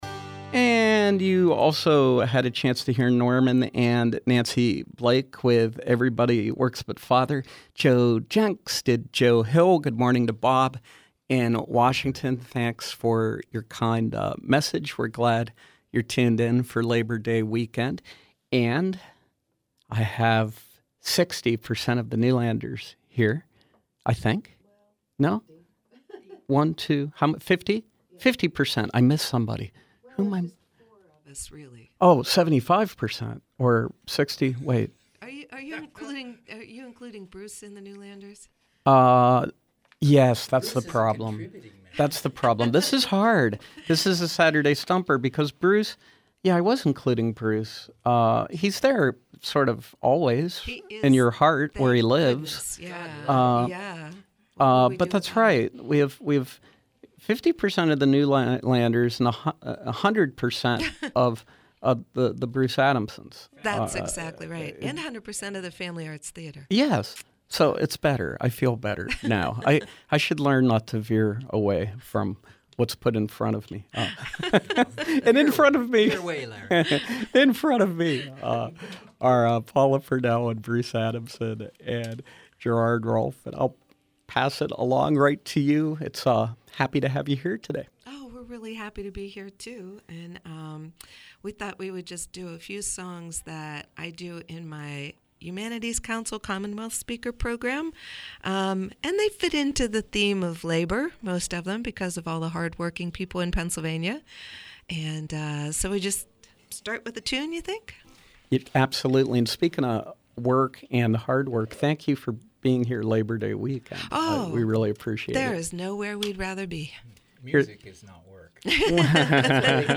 traditional folk quartet
perform live in our studios.